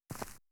footstep_earth_left.wav